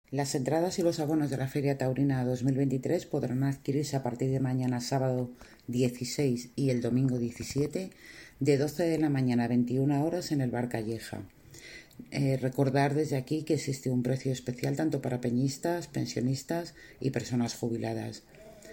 Declaraciones de la concejala Charo Martín 1